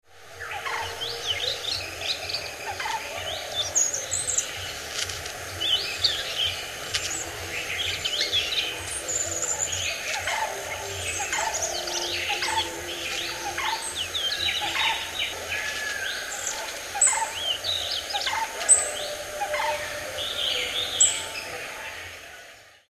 This time in January the flycatcher in Tanamalvila was only singing its subsong, quite long phrases comprising of high-pitched warbling and squeaking notes, which is sung quite softly (as usual with singing subsongs by the song birds).
It has been edited slightly with reduction of unwanted background sounds to some extent. Subsong is the soft, high-pitched notes heard in a continuous uttering, and sounds of some other birds are also evident in background of the track (i.e.  Brown-headed Barbet, Indian Peafowl and Pale-billed Flowerpecker ).
Yellow-rumped-Flycatcher-subsong.mp3